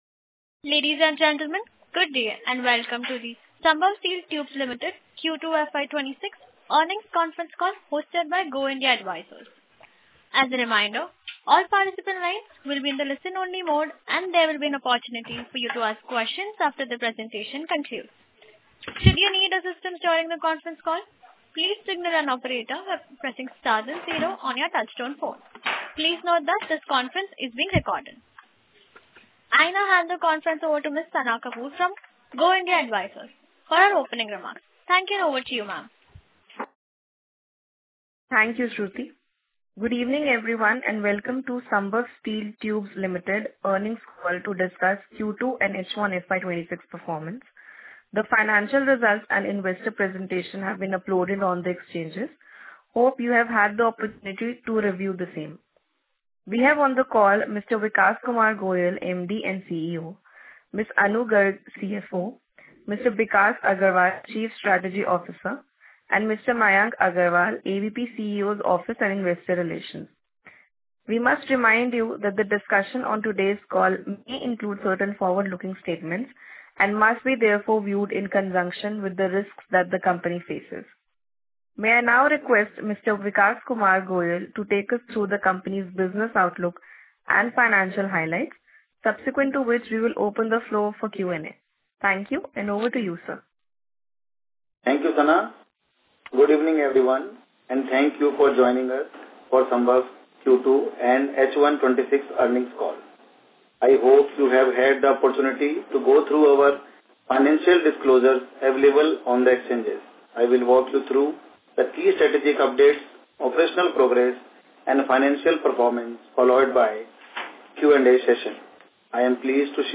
Sambhv-Q2FY26-Concall-Audio.mp3